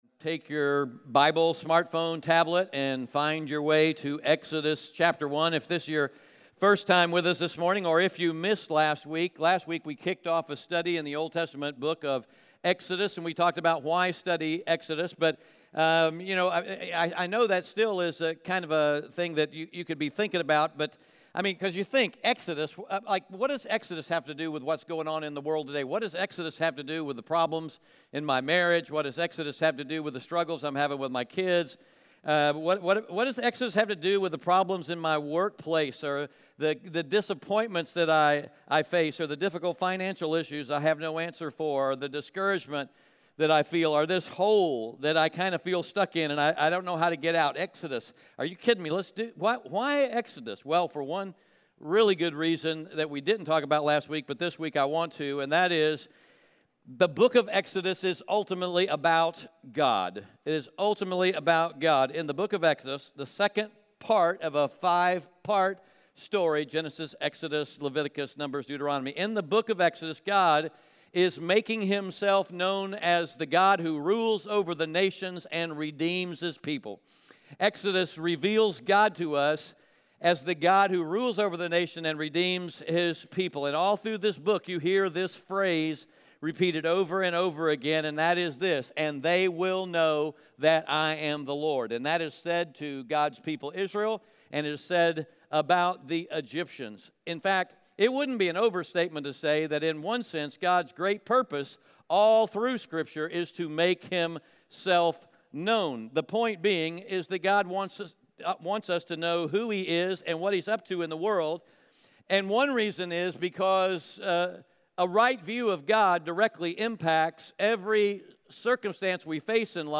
Audio Sermon Notes (PDF) Ask a Question *We are a church located in Greenville, South Carolina.